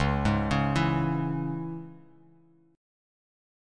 Listen to C#m strummed